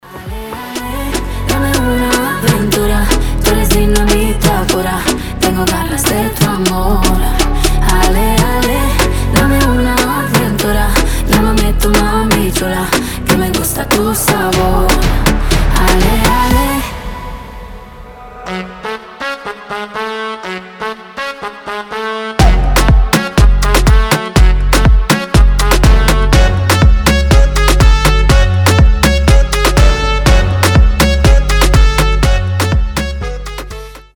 • Качество: 320, Stereo
ритмичные
заводные
Moombahton
труба
Reggaeton
Заводной рингтон